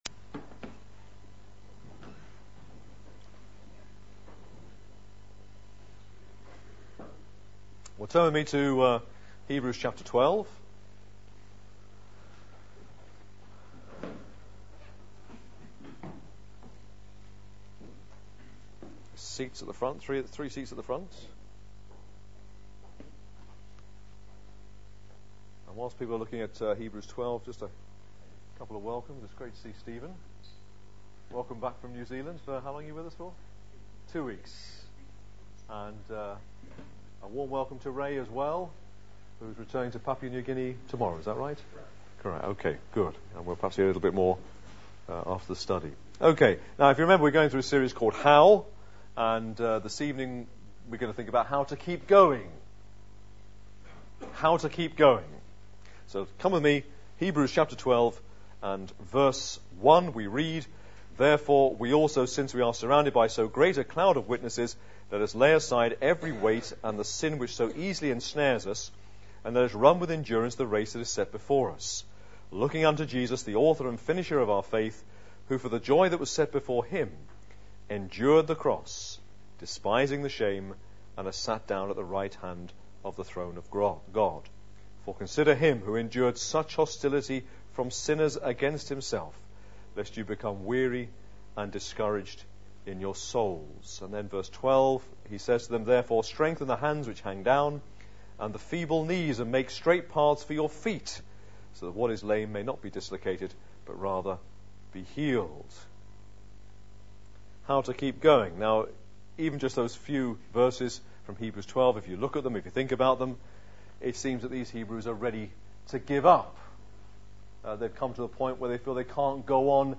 Wednesday Bible Studies & Prayer Meetings